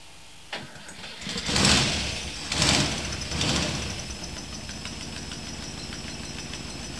It started! The noise was astonishing!
started it again and let it run for a while.
v8_start.wav